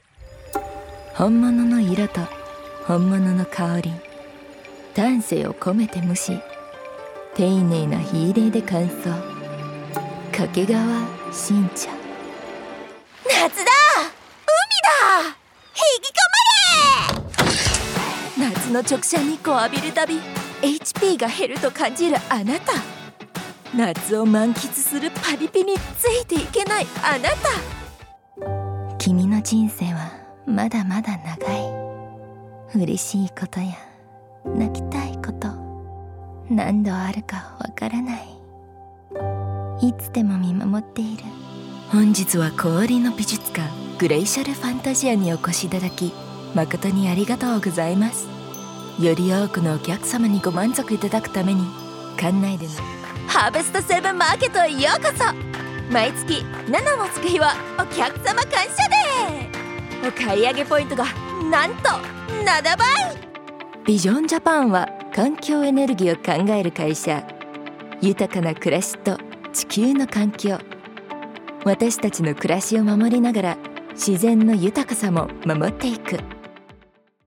Voice Demo Reels
Narration Reel - Japanese